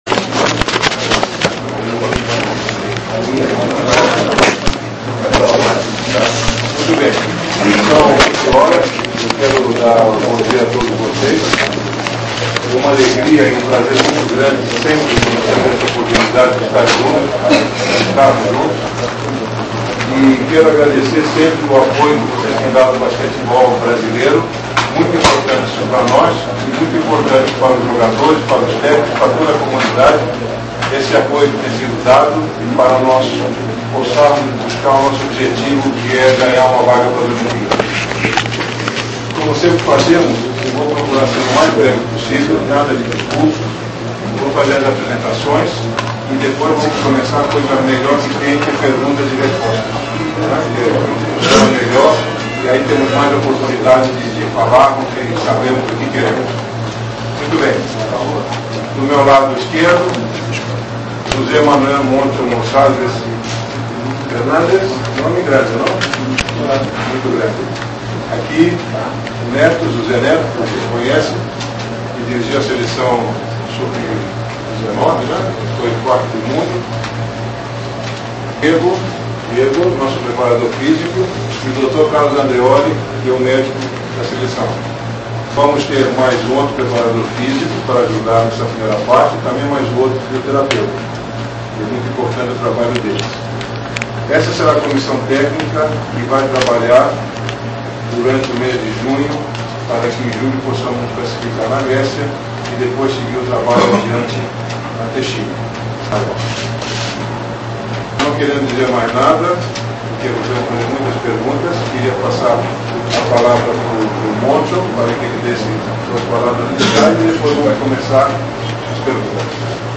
O Draft Brasil marcou presença na entrevista coletiva que serviu para apresentar o novo técnico da seleção brasileira de basquete, o espanhol Moncho Monsalve.
Moncho chegou ao auditório da CBB com os olhos marejados, visivelmente emocionado para a sua apresentação oficial.
coletiva_moncho.mp3